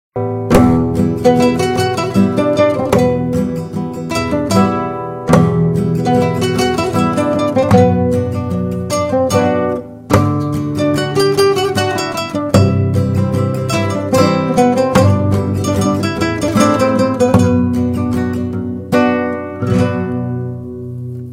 یه مدل جدید از سه تار زدن هم هست که ابداعی هست که اینکه مثل گیتار بزنیم